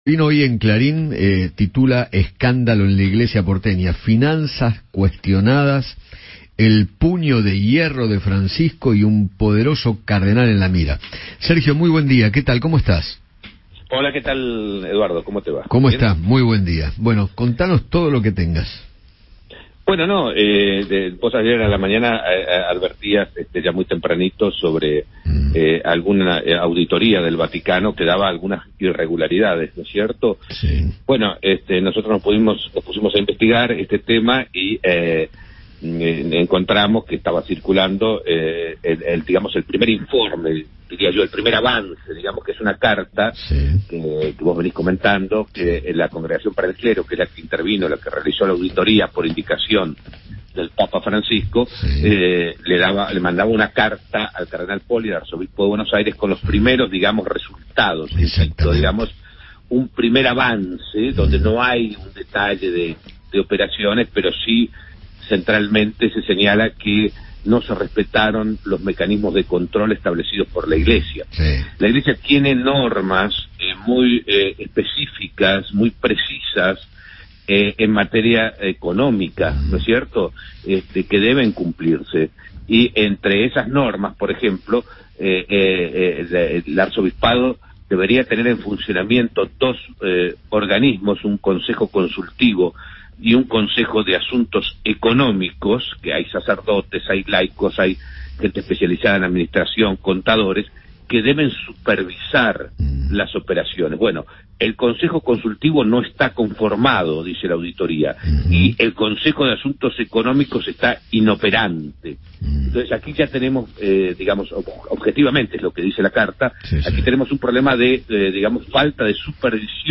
Eduardo Feinmann dialogó con Sergio Rubin, periodista especializado en temas del Vaticano, sobre la polémica en torno a irregularidades del Cardenal Mario Poli en operaciones inmobiliarias.